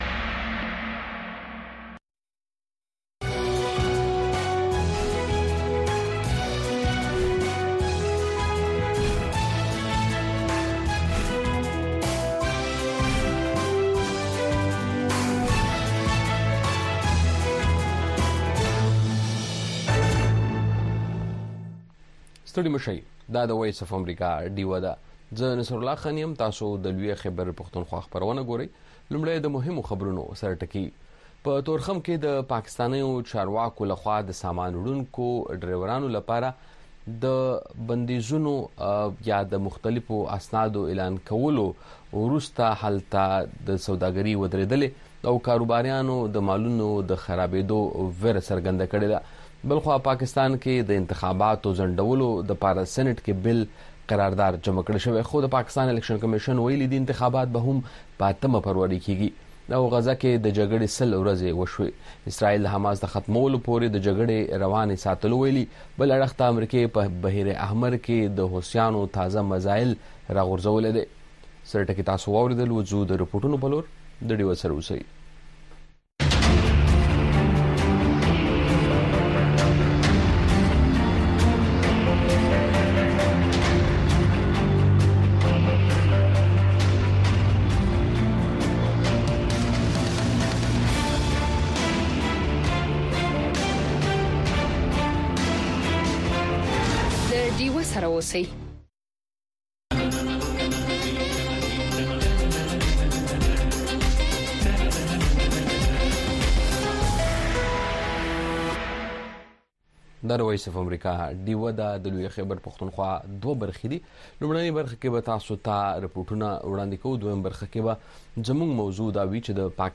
خبرونه
د وی او اې ډيوه راډيو خبرونه چالان کړئ اؤ د ورځې د مهمو تازه خبرونو سرليکونه واورئ.